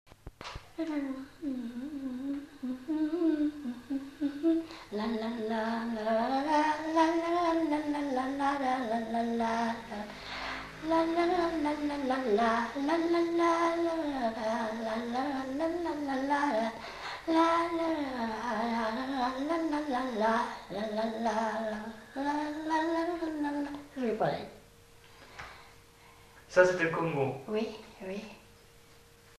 Aire culturelle : Gabardan
Genre : chant
Effectif : 1
Type de voix : voix de femme
Production du son : fredonné
Danse : congo